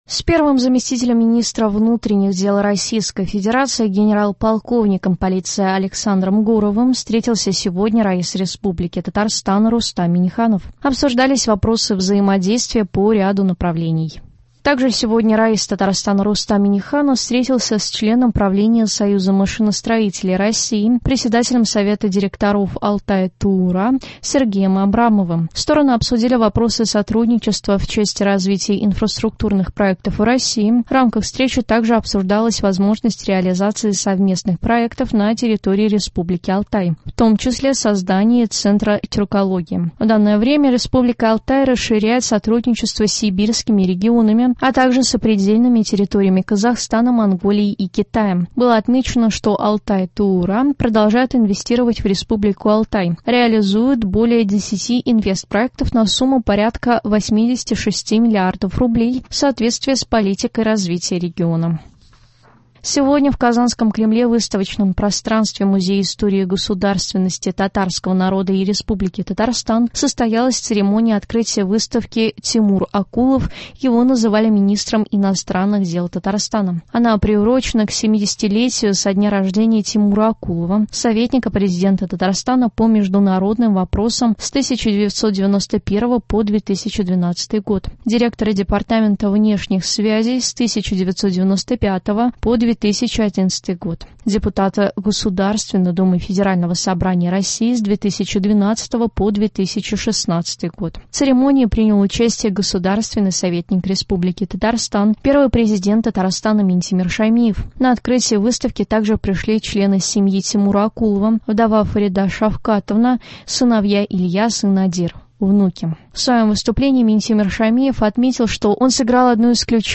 Новости (10.04.23)